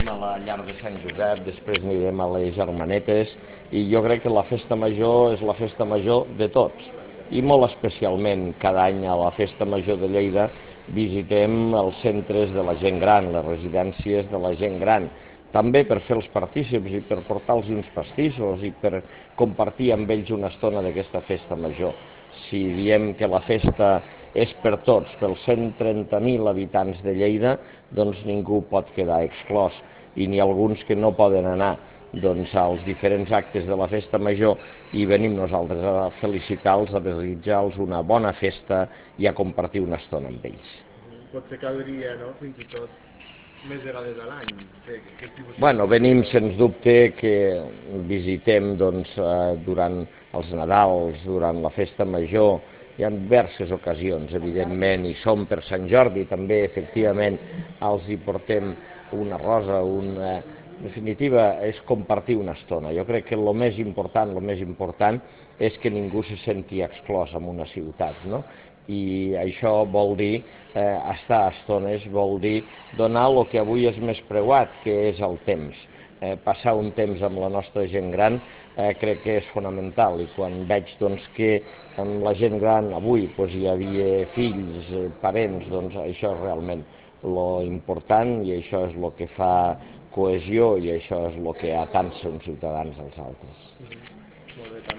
ARXIU DE VEU on Ros explica la visita a les llars de gent gran
arxiu-de-veu-on-ros-explica-la-visita-a-les-llars-de-gent-gran